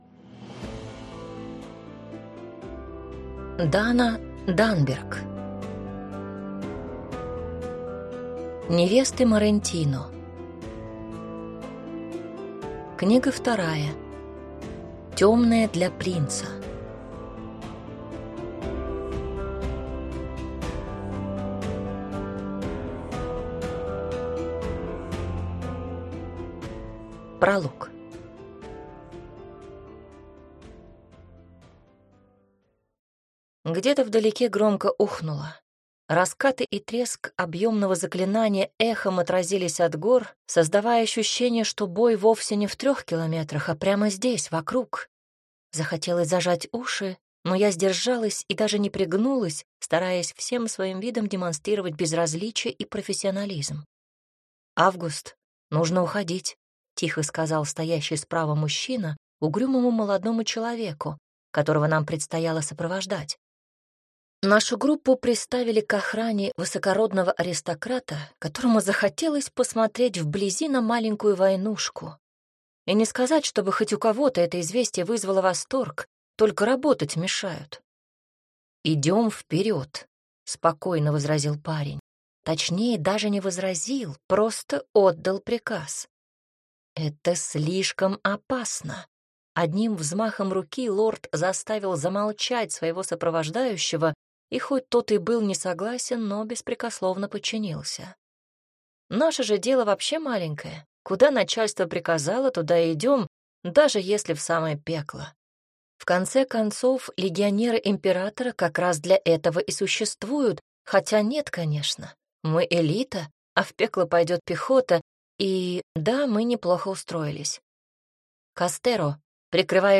Аудиокнига Темная для принца. Книга 2 | Библиотека аудиокниг